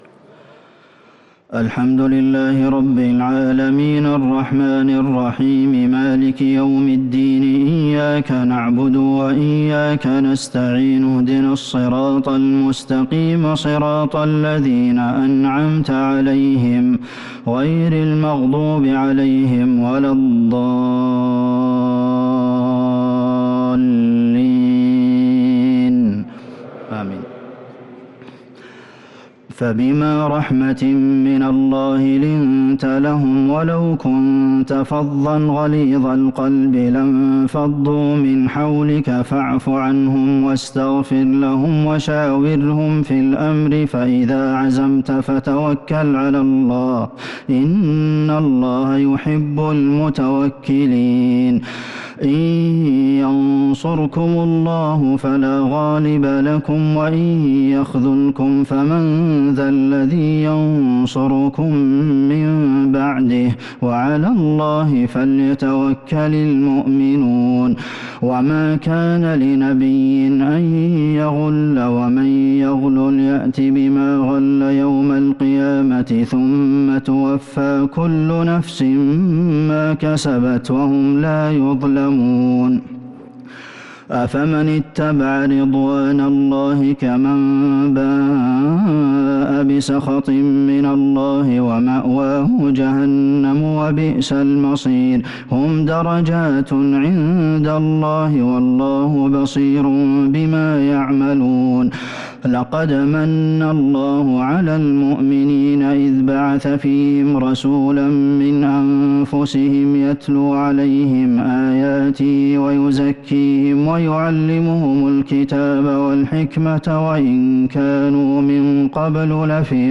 صلاة التراويح ليلة 5 رمضان 1443 للقارئ عبدالمحسن القاسم - التسليمتان الأخيرتان صلاة التراويح